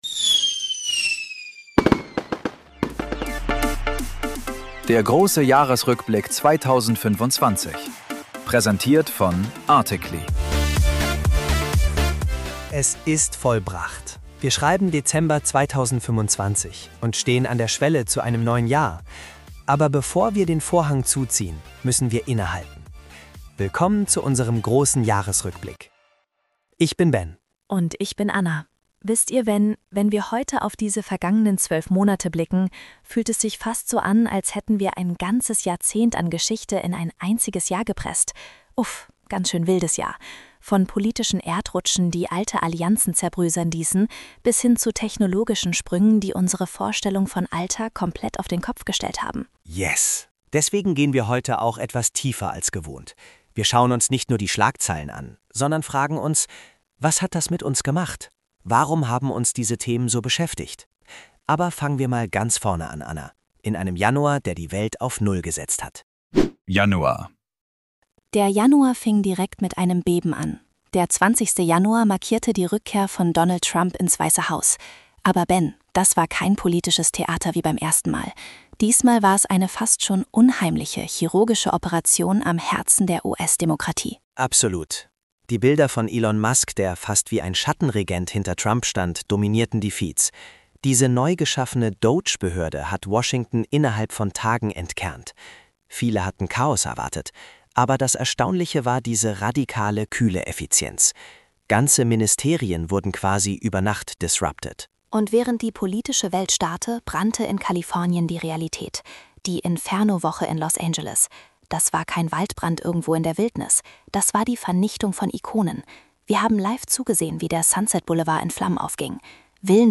Diese Podcast-Episode und deren Skript wurden vollständig KI-generiert von ARTICLY.